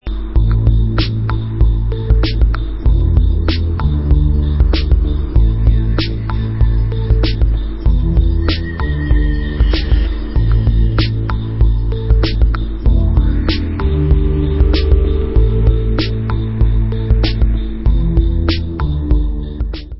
sledovat novinky v oddělení Dance/House